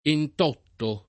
[ ent 0 tto ]